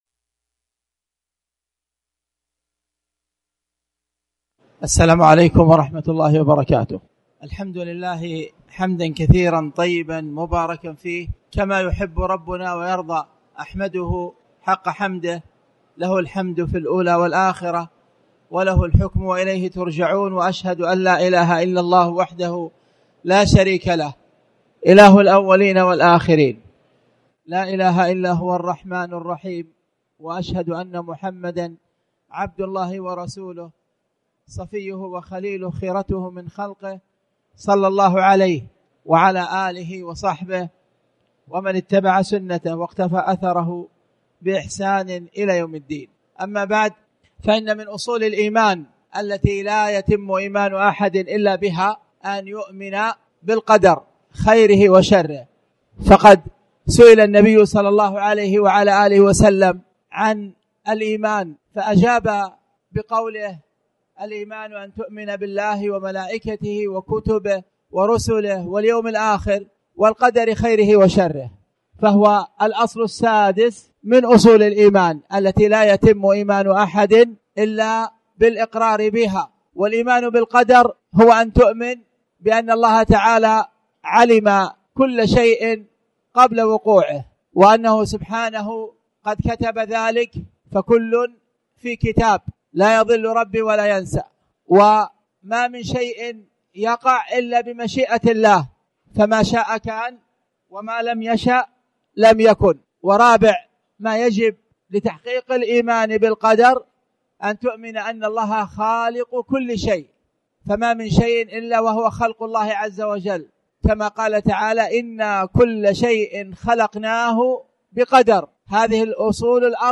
تاريخ النشر ٣ شعبان ١٤٣٩ هـ المكان: المسجد الحرام الشيخ